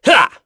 Lusikiel-Vox_Attack2.wav